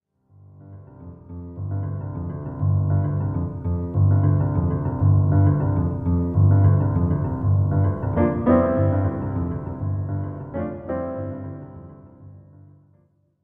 Music Transition; Modern Jazz Piano Fade In And Out.